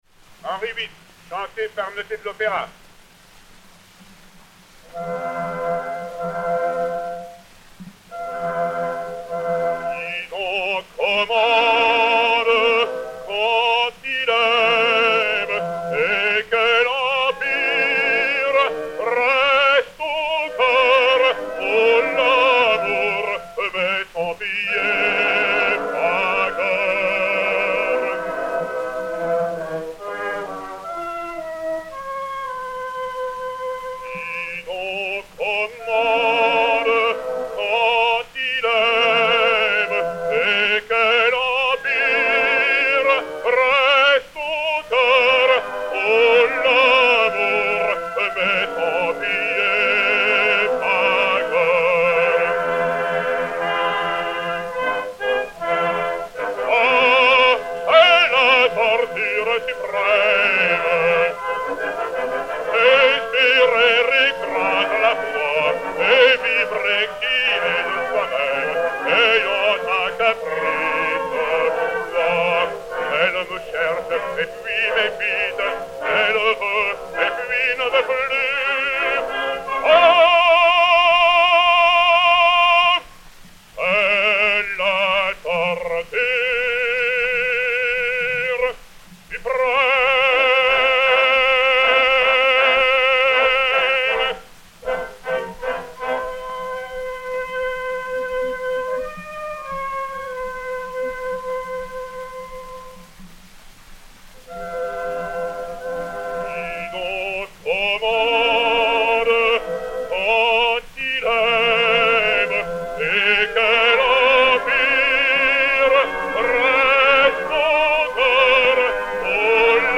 enr. à Paris en 1910/1911